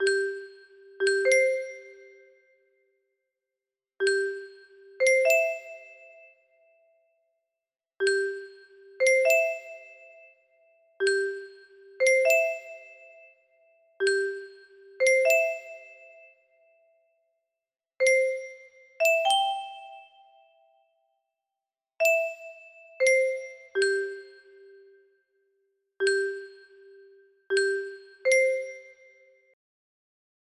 Taps music box melody
Grand Illusions 30 (F scale)